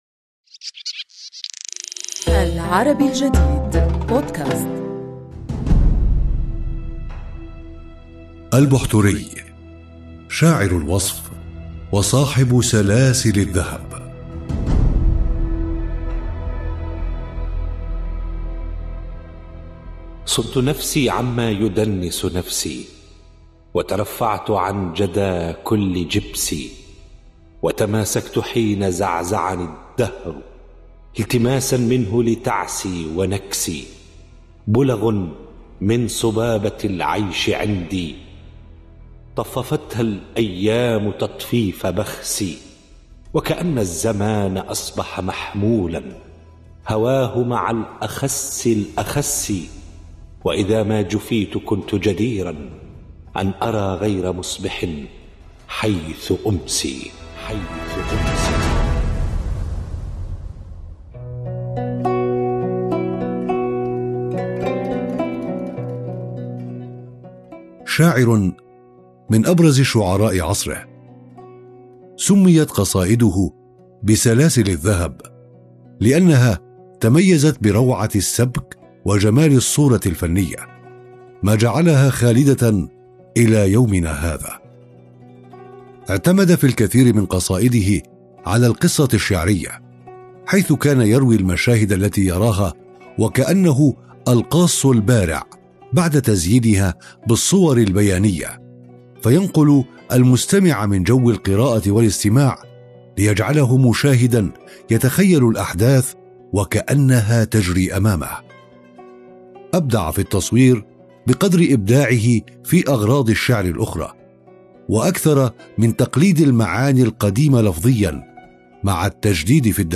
الراوي